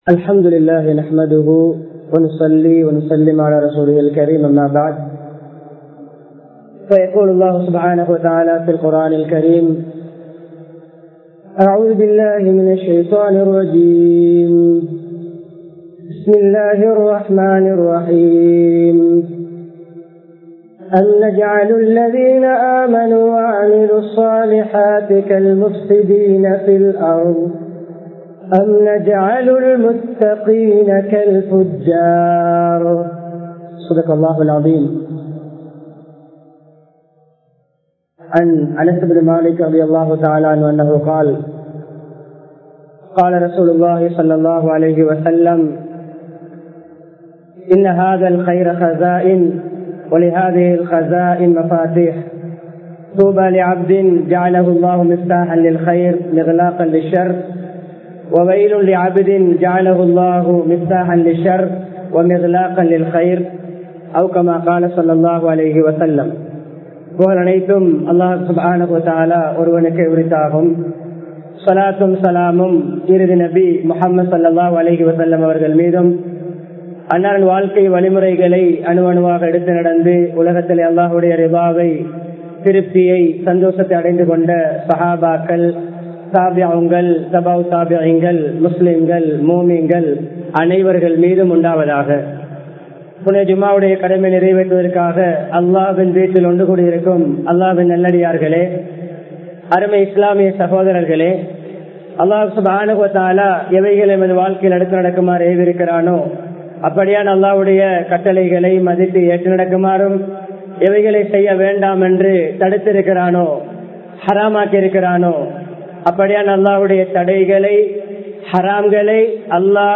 நல்லவைகளுக்கு திறவுகோலாக இருப்போம் | Audio Bayans | All Ceylon Muslim Youth Community | Addalaichenai
Munawwara Jumua Masjidh